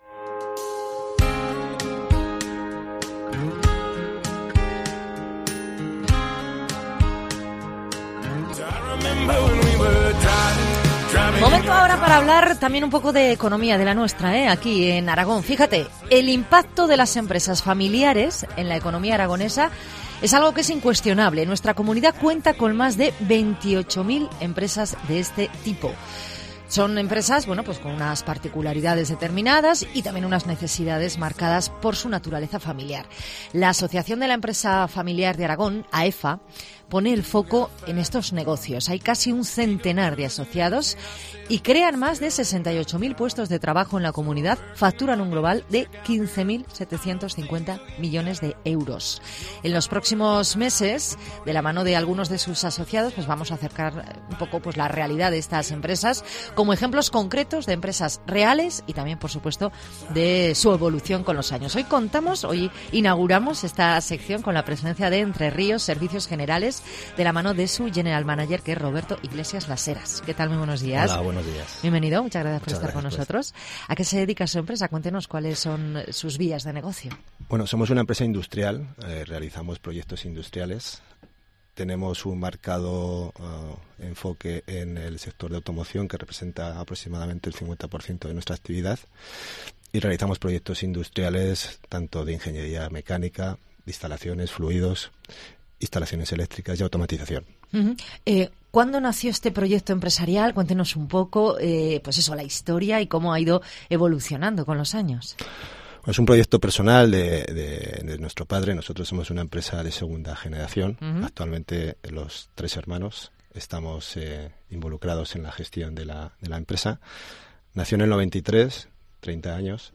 Entrevista AEFA